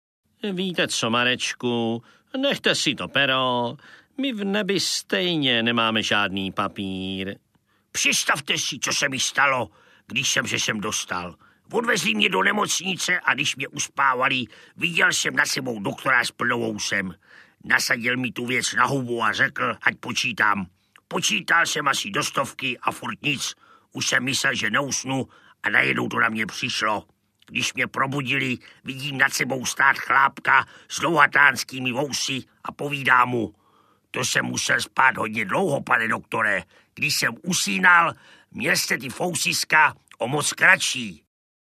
Spiritistická seance audiokniha